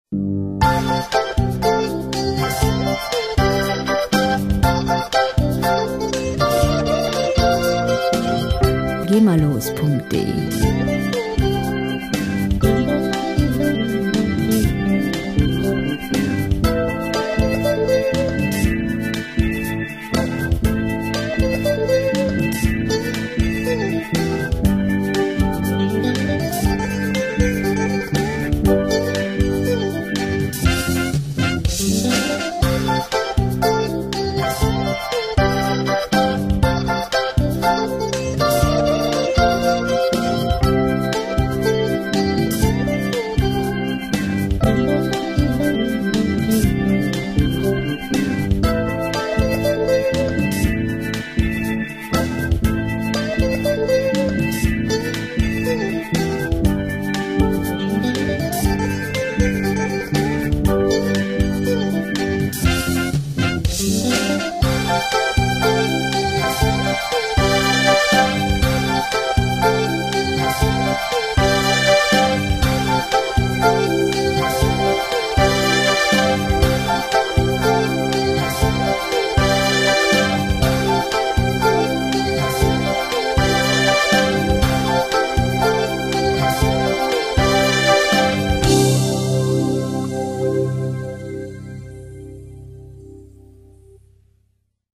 • Rock Pop